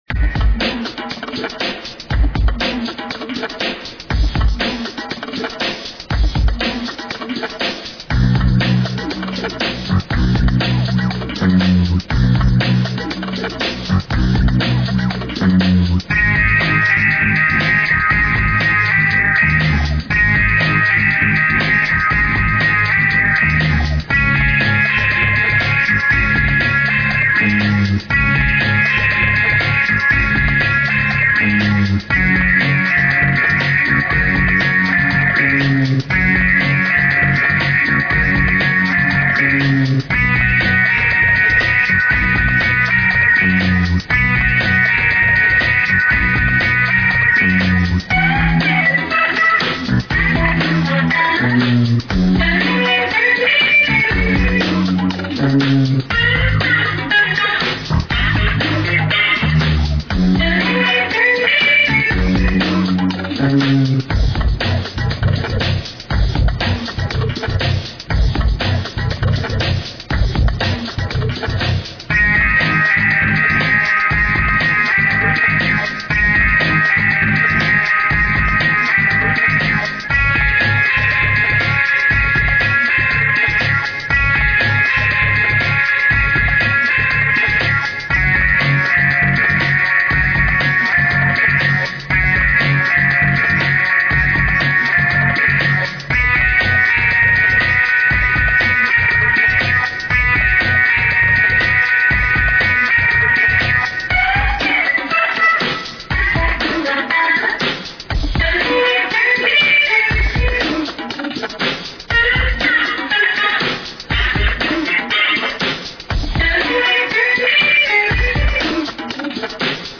An upbeat and funky track with percussion and
rock guitars.
Royalty Free Music for use in any type of